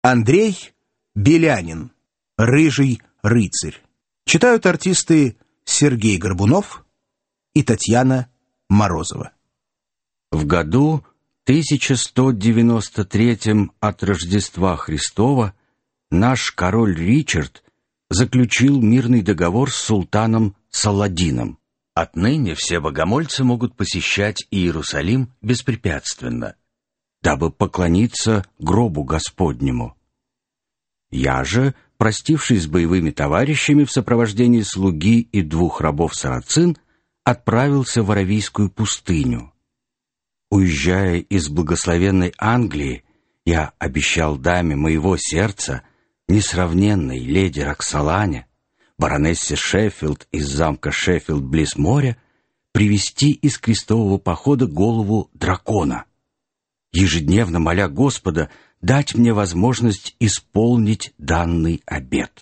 Аудиокнига Рыжий рыцарь | Библиотека аудиокниг